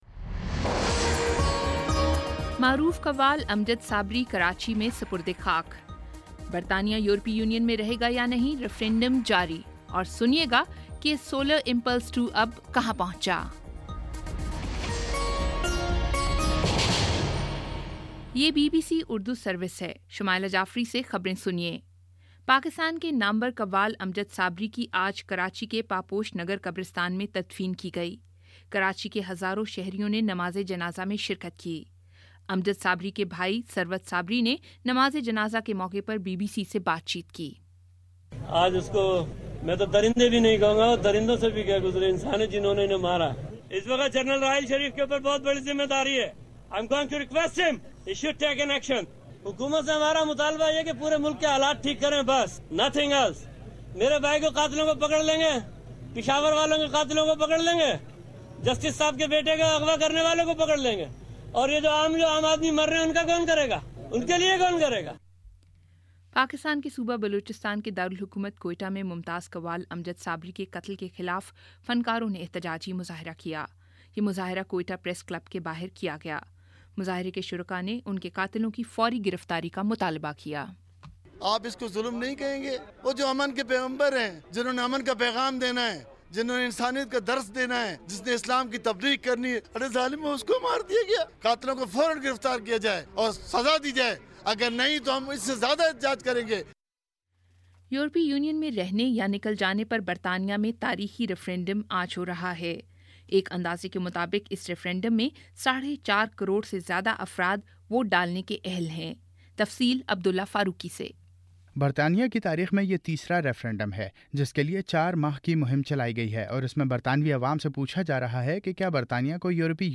جون 23 : شام چھ بجے کا نیوز بُلیٹن